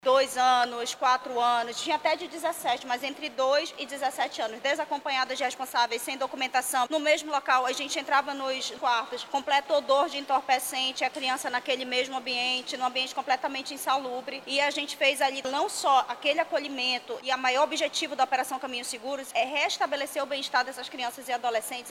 Ainda segundo a delegada, os menores foram encontrados em situação de vulnerabilidade.